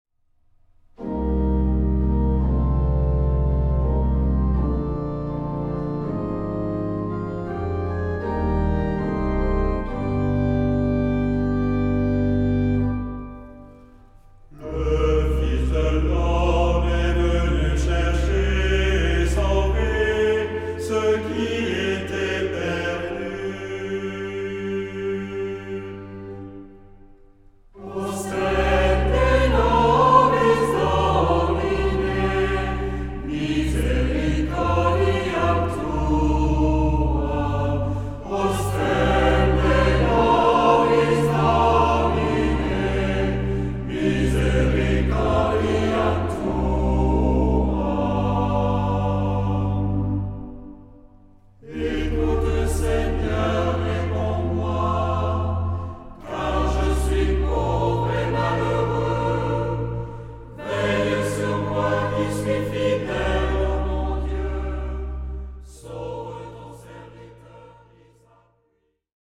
Genre-Stil-Form: Tropar ; Psalmodie
Charakter des Stückes: andächtig
Chorgattung: SATB  (4 gemischter Chor Stimmen )
Instrumente: Orgel (1) ; Melodieinstrument (ad lib)
Tonart(en): c-moll